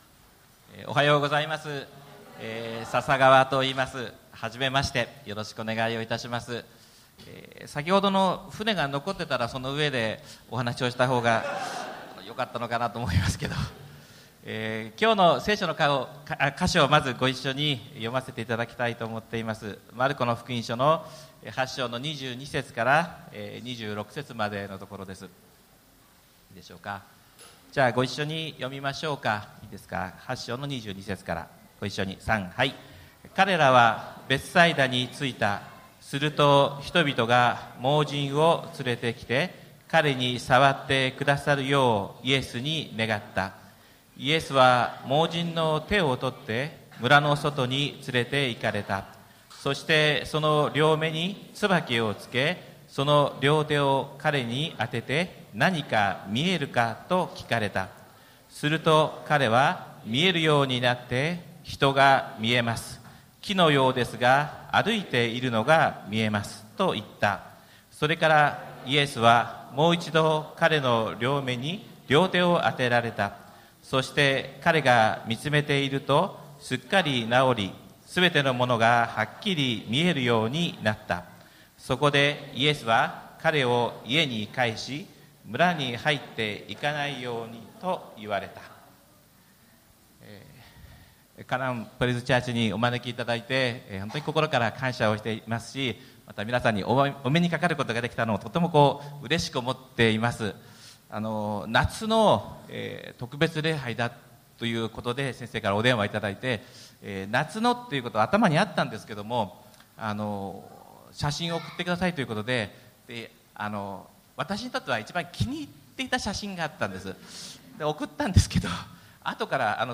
日曜礼拝